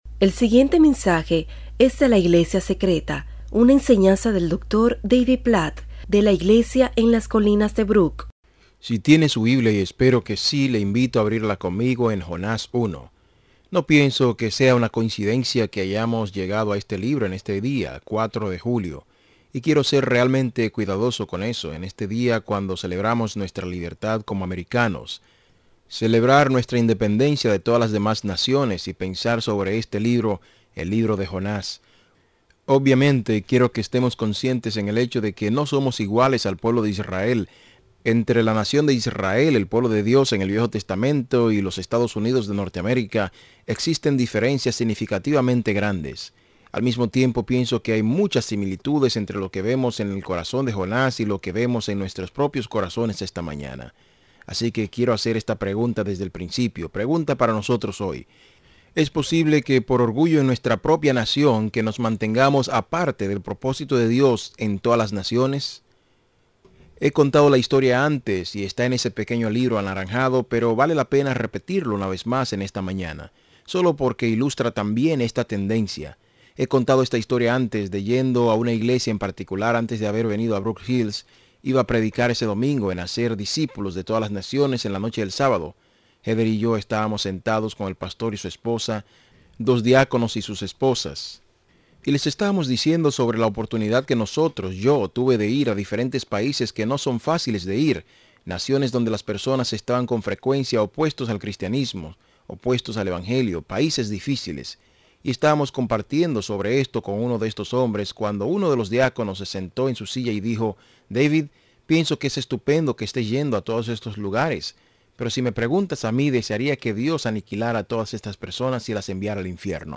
En este mensaje sobre Jonás, el pastor David Platt nos enseña que Dios es soberano, misericordioso y se preocupa por las naciones.
Transcripción